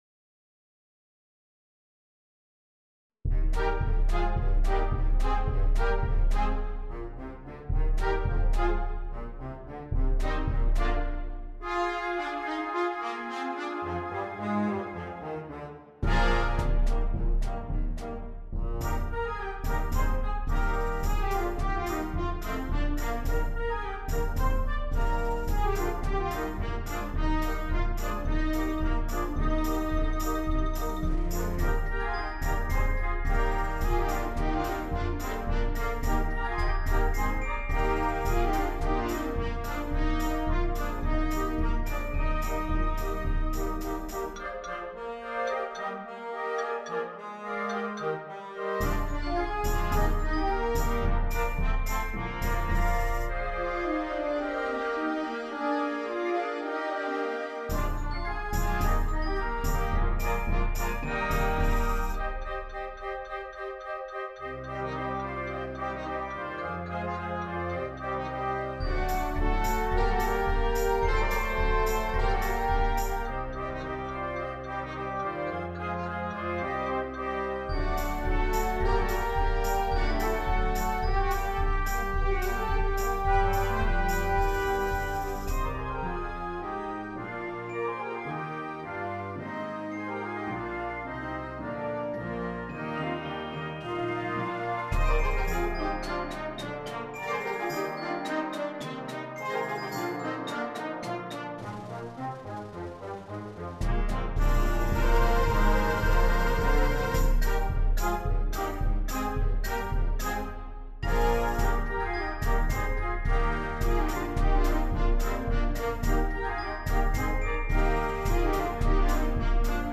a contemporary concert band work